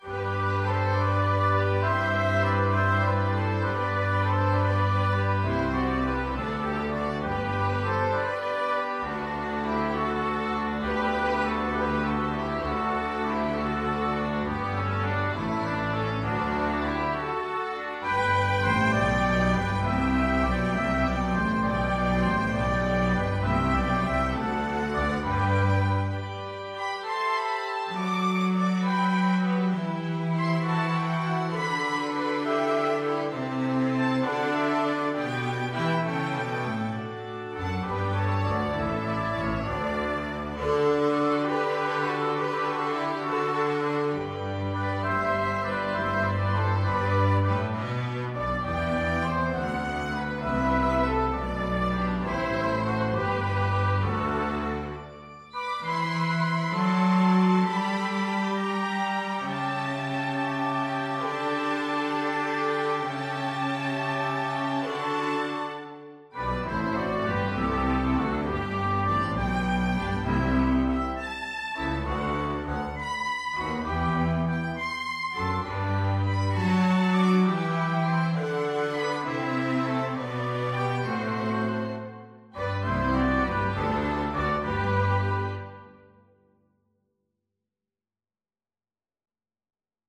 Violin
Trumpet
Double Bass
Keyboard
12/8 (View more 12/8 Music)
Largo